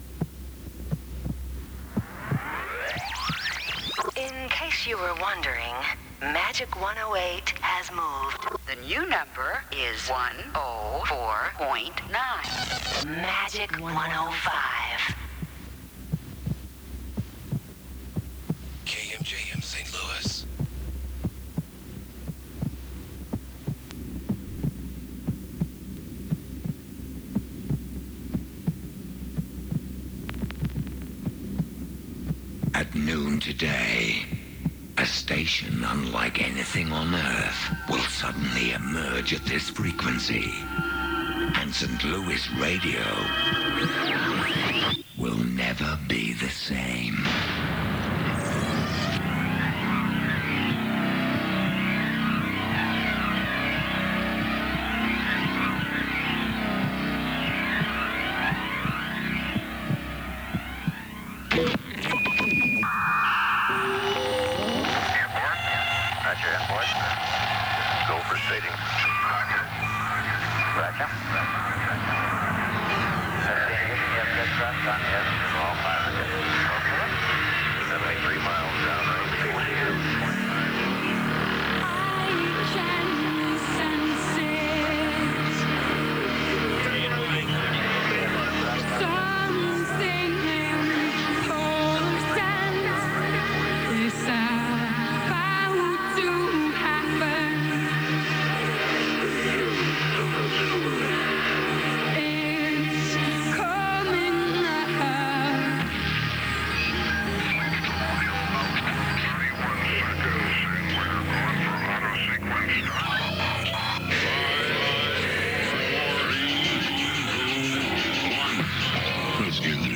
KSLZ Automation aircheck · St. Louis Media History Archive
Original Format aircheck